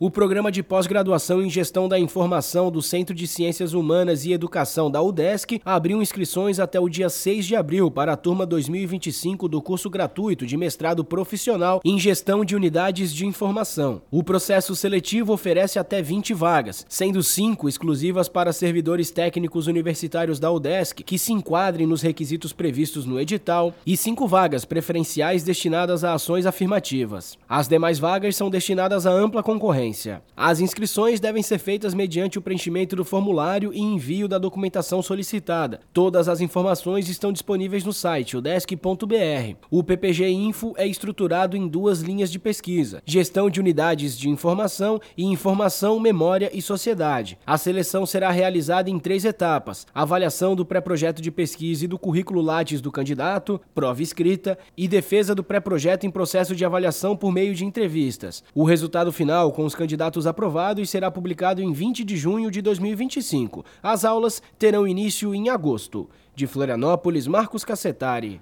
BOLETIM – Mestrado em Gestão da Informação da Udesc Faed abre 20 vagas para a turma deste ano